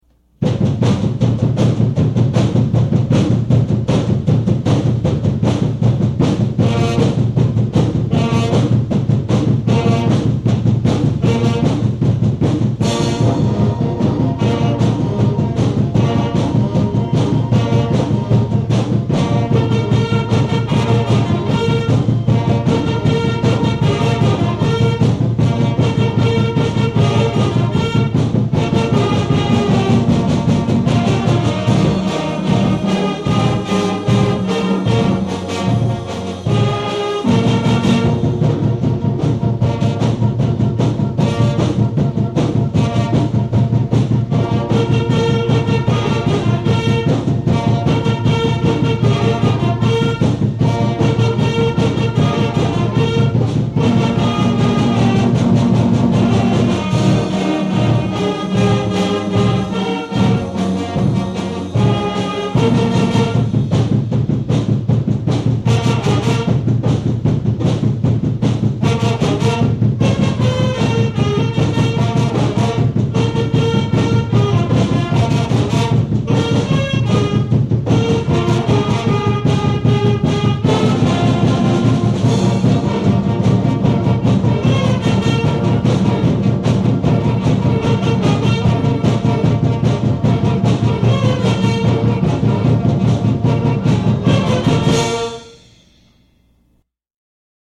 Click on the titles below to hear the Greatest Pep Band of All-Time play your favorite hits!